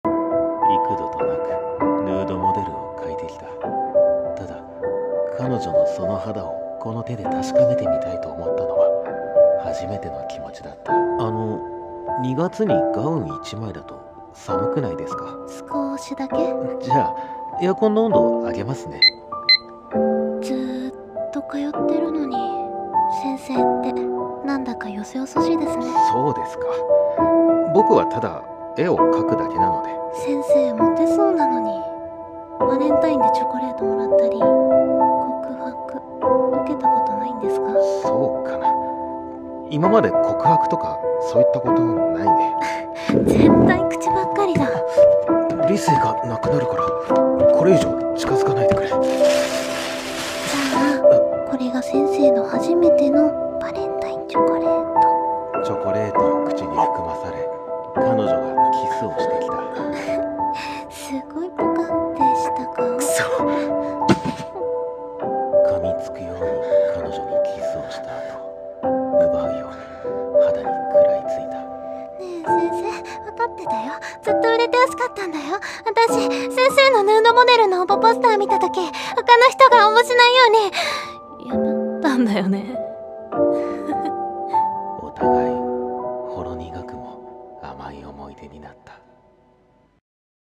【2人声劇】Model